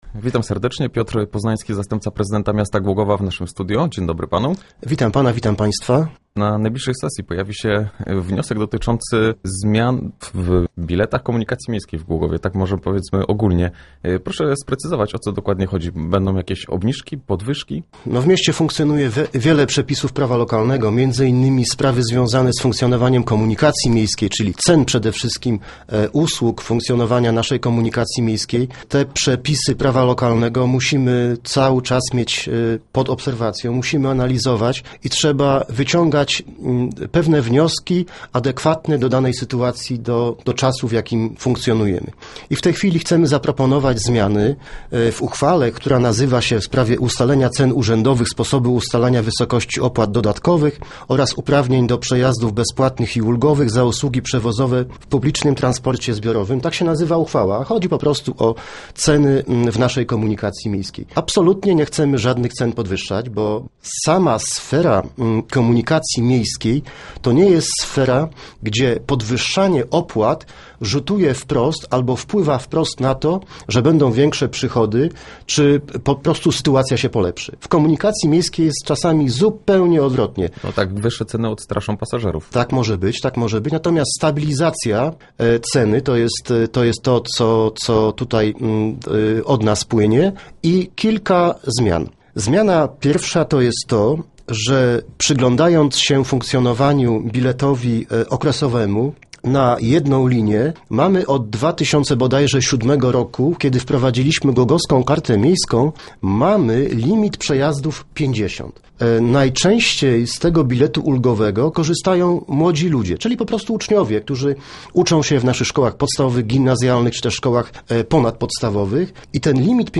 1224_poznanski_re.jpgWiększy limit przejazdów w tej samej cenie i kilka dni z darmowymi przejazdami, to podstawowe zmiany, jakie władze Głogowa chcą wprowadzić w Komunikacji Miejskiej. – Po ich wprowadzeniu ocenimy, jak funkcjonują i wyciągniemy odpowiednie wnioski – zapowiada zastępca prezydenta miasta Piotr Poznański.